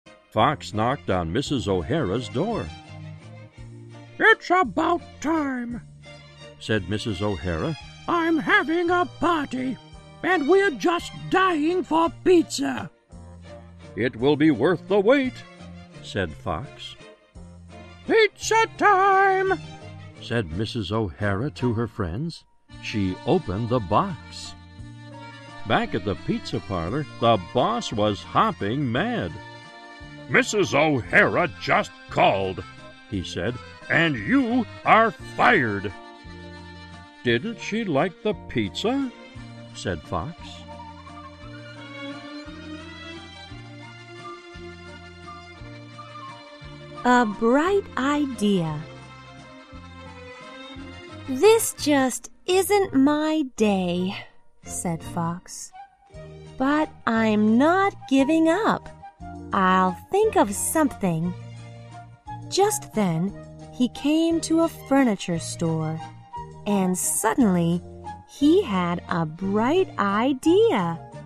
在线英语听力室小狐外传 第82期:奥哈拉太太的听力文件下载,《小狐外传》是双语有声读物下面的子栏目，非常适合英语学习爱好者进行细心品读。故事内容讲述了一个小男生在学校、家庭里的各种角色转换以及生活中的趣事。